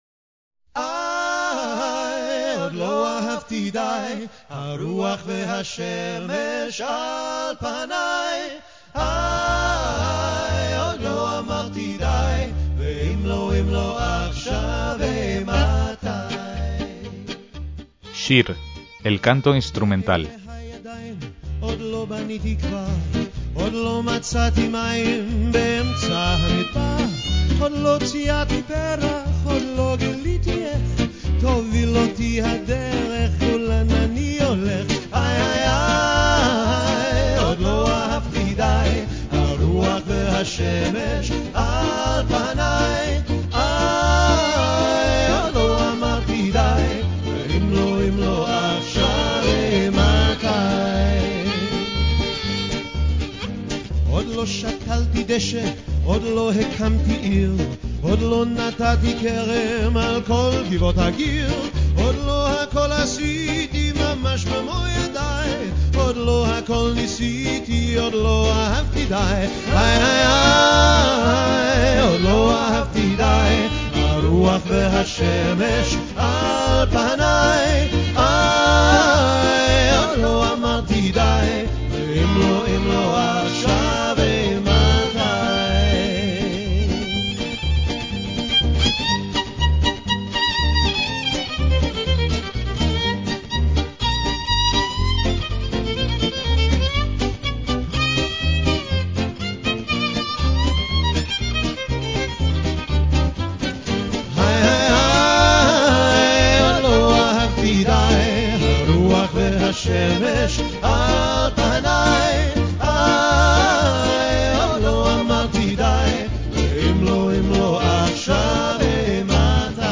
banda de música tradicional judía de Londres
guitarra y voz
clarinete y voz